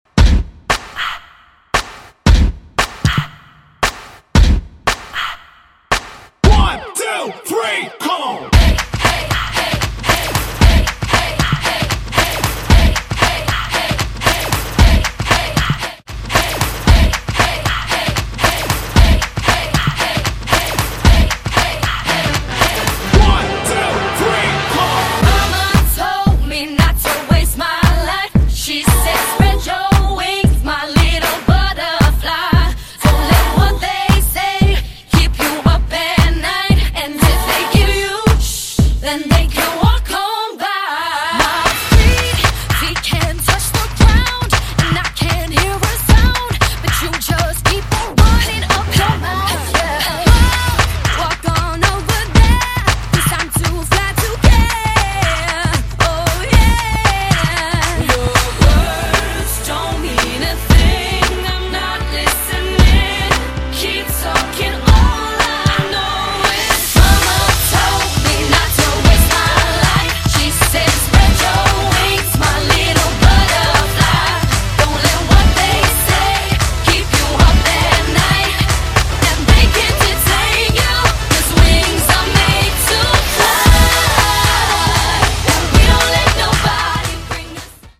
Genre: RE-DRUM Version: Semi-Clean BPM: 128 Time